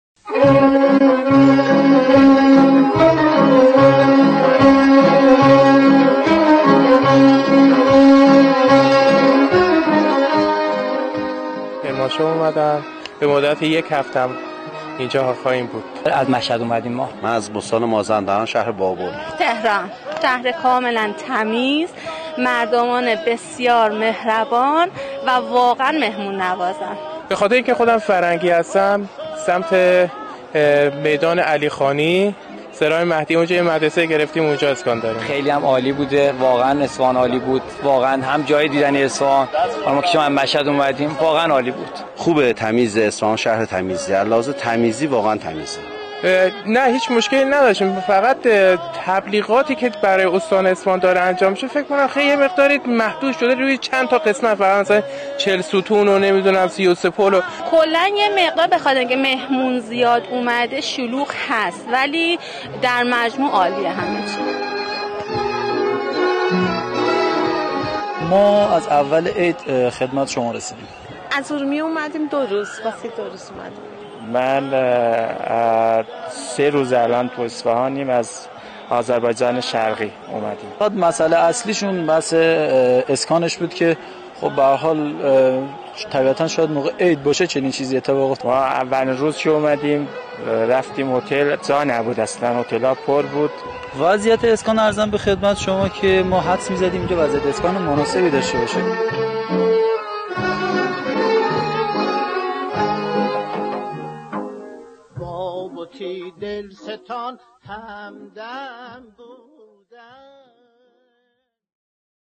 مسافران نوروزی از سفر به اصفهان می‌گویند